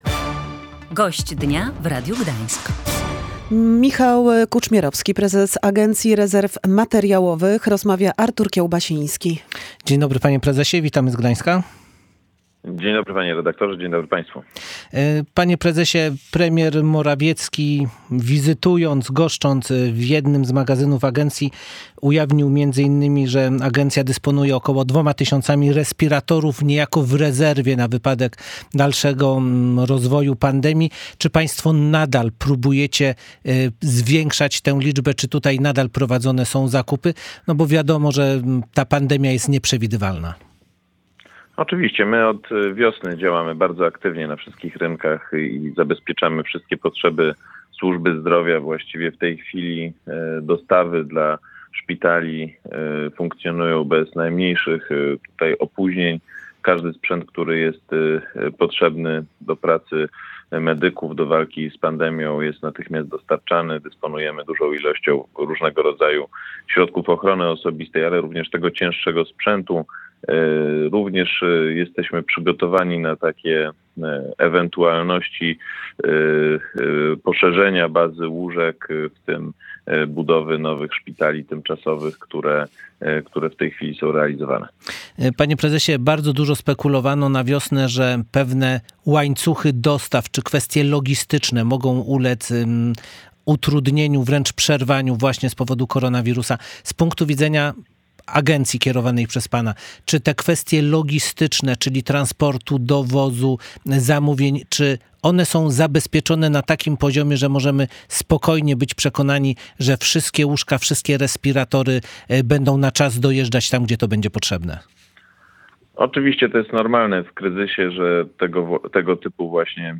Już od wiosny działamy bardzo aktywnie na wszystkich rynkach i zabezpieczamy wszelkie potrzeby służby zdrowia – mówił gość Radia Gdańsk Michał Kuczmierowski, prezes Agencji Rezerw Materiałowych. Podkreślił, że dostawy potrzebne sprzętu dla szpitali działają bez opóźnień.